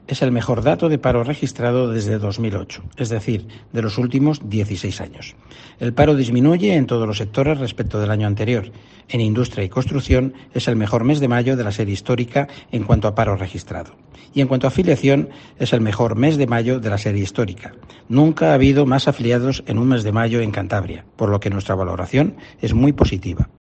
Eduardo Arasti, consejero de Empleo